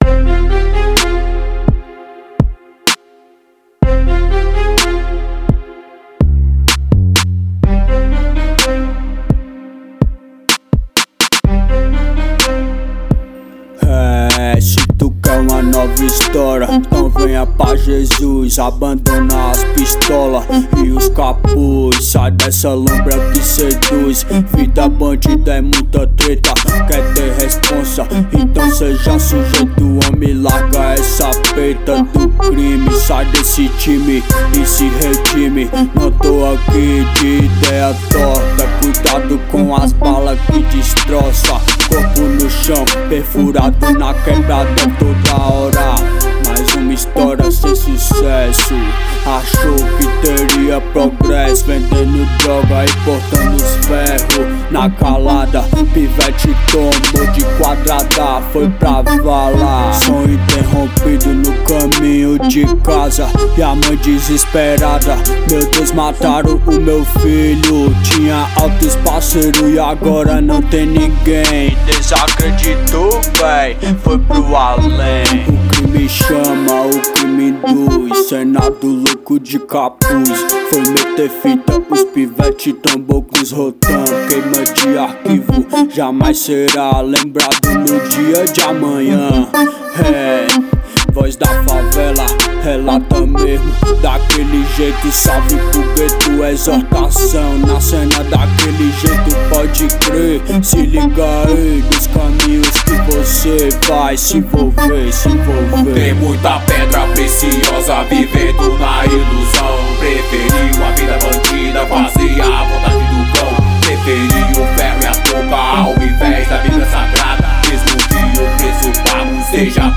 é uma música